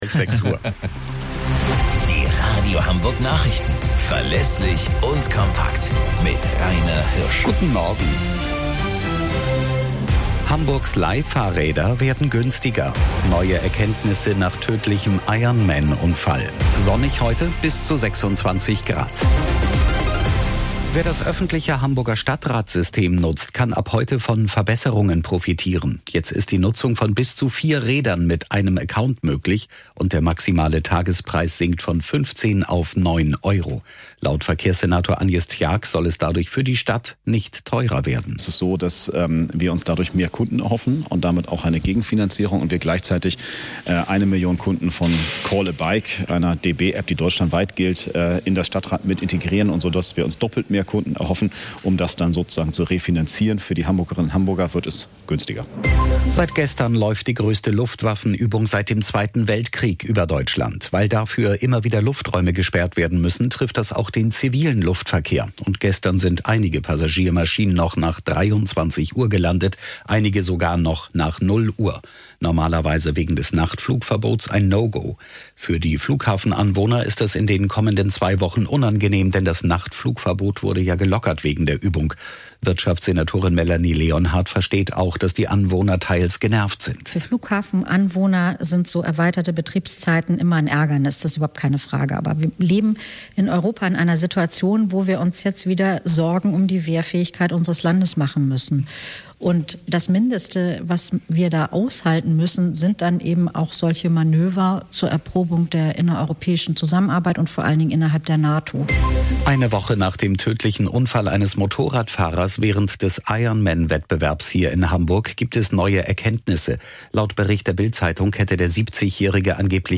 Radio Hamburg Nachrichten vom 13.06.2023 um 13 Uhr - 13.06.2023